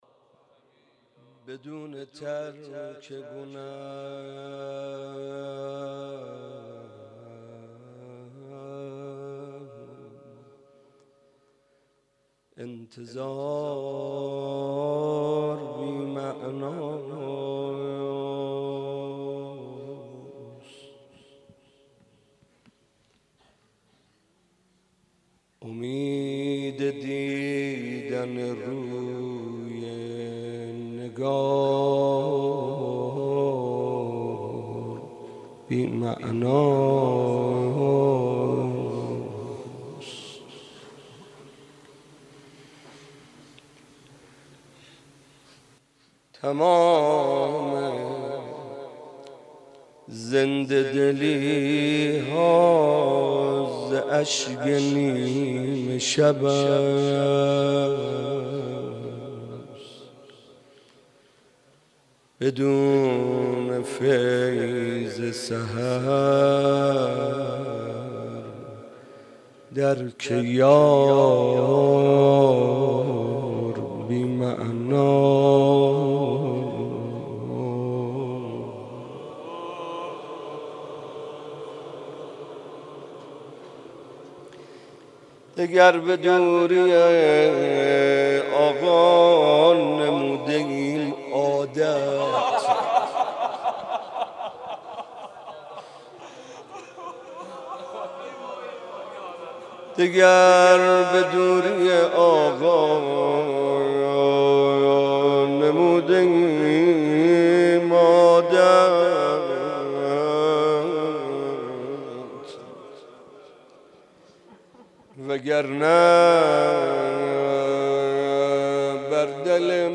روضه طفلان حضرت زینب (س)